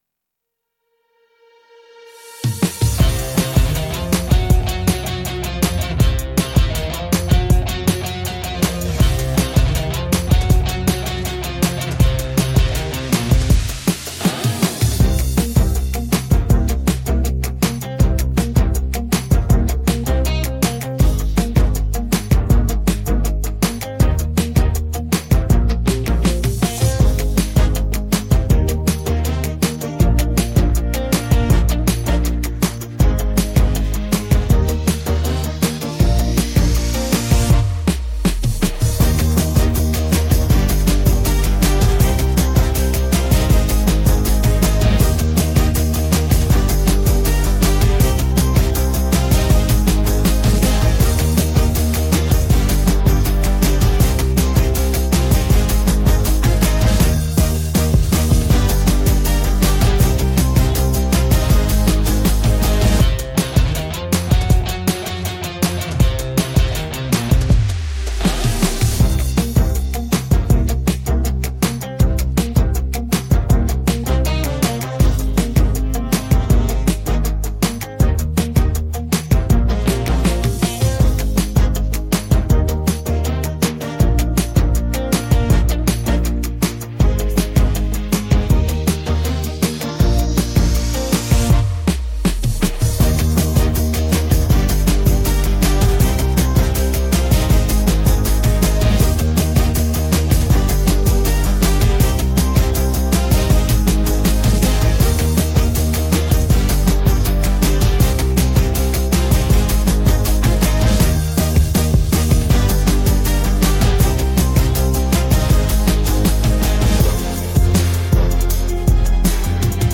• Категория: Детские песни
караоке